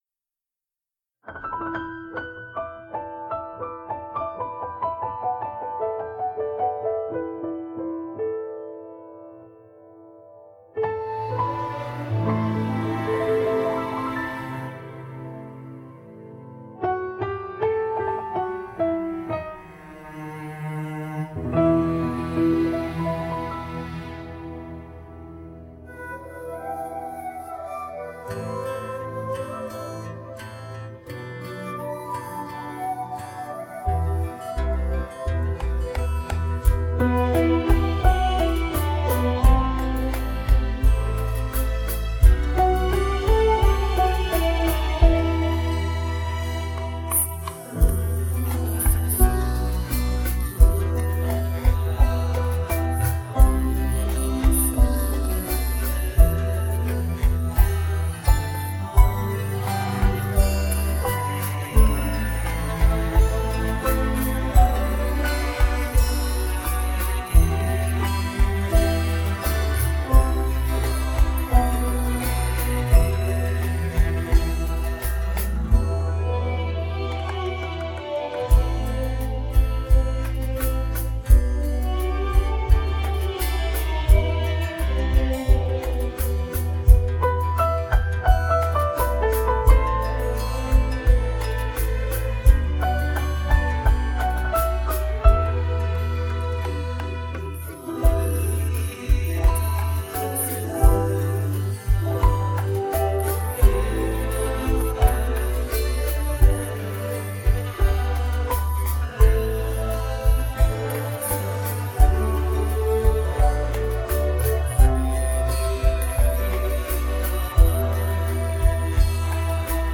Play Karaoke & Sing with Us